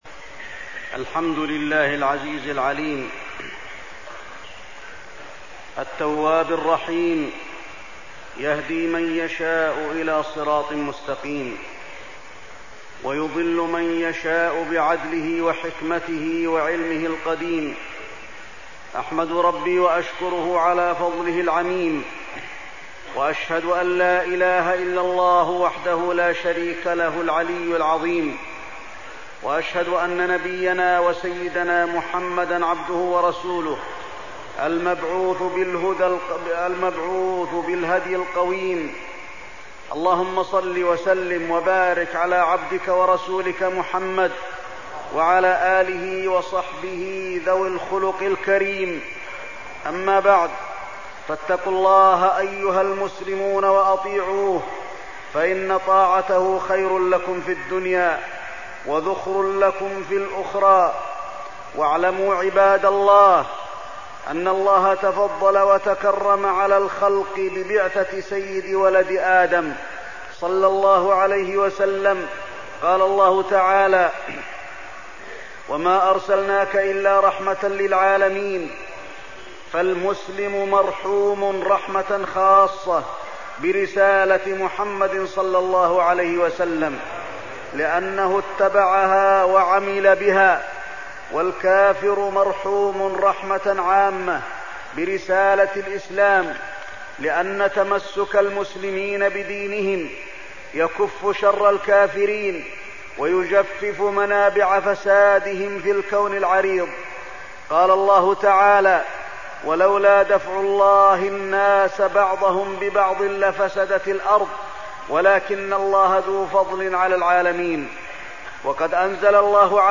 تاريخ النشر ١٧ ذو القعدة ١٤١٦ هـ المكان: المسجد النبوي الشيخ: فضيلة الشيخ د. علي بن عبدالرحمن الحذيفي فضيلة الشيخ د. علي بن عبدالرحمن الحذيفي الدعوة إلى السنة